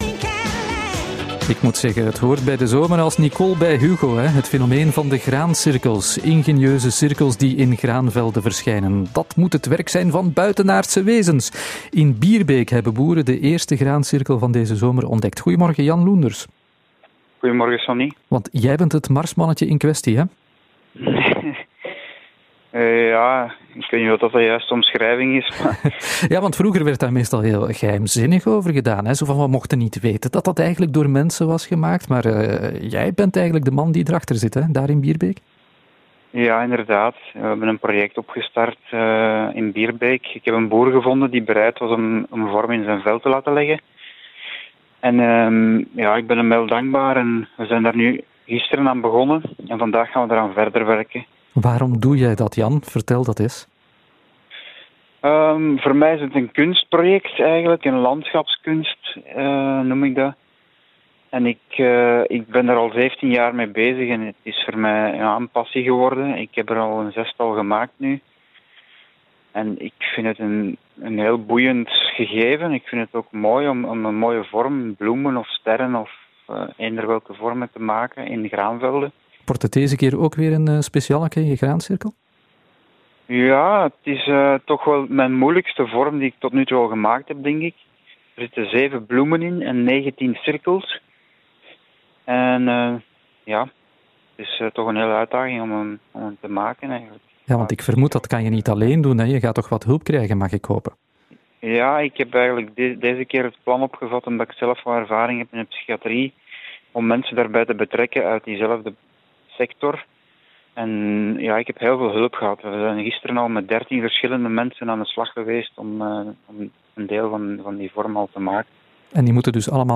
Beluister een interview op Radio 2 hierover.